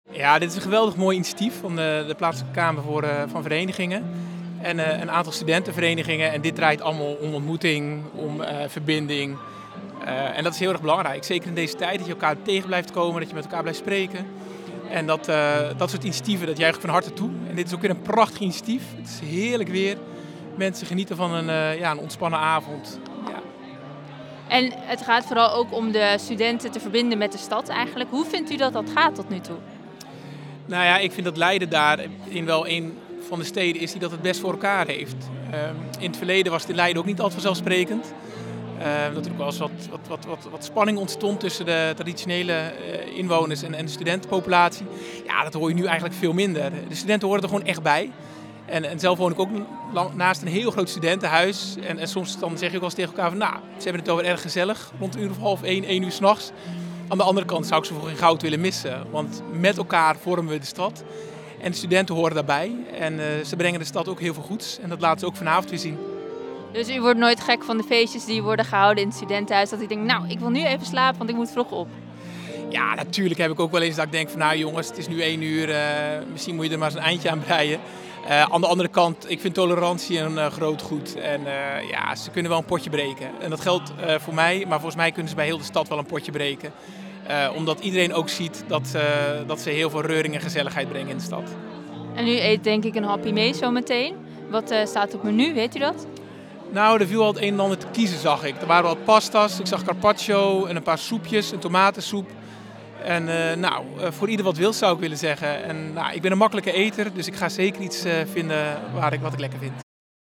Burgemeester Peter Heijkoop in gesprek met verslaggever